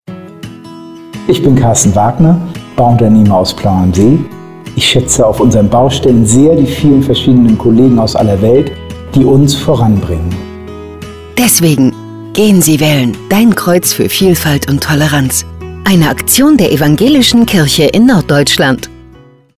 Um möglichst viele Menschen zu erreichen, haben wir Radiospots in norddeutschen Sendern geschaltet, die wir in Kooperation mit dem Evangelischen Presseverband Nord produziert haben. Sechs Menschen aus unserer Landeskirche haben mitgemacht und ein „Testimonial“ eingesprochen.